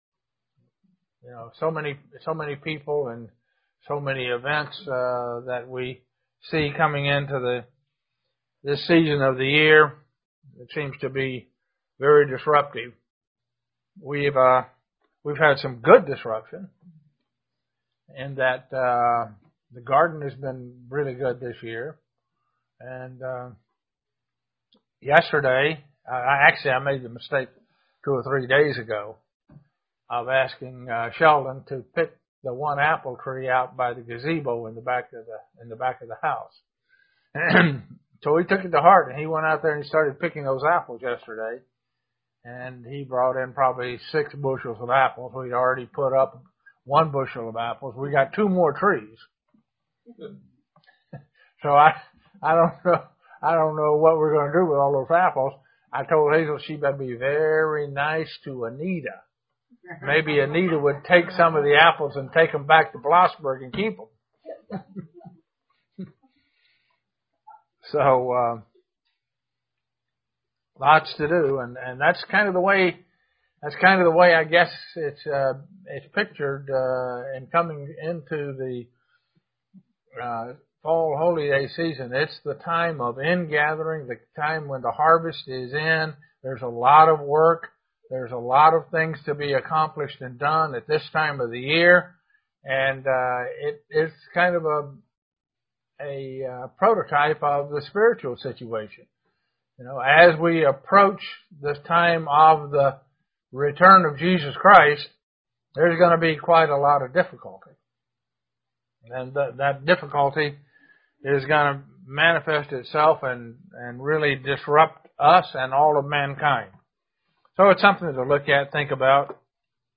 Given in Elmira, NY
Print The 6th beatitude as it relates to the Day of Atonement UCG Sermon Studying the bible?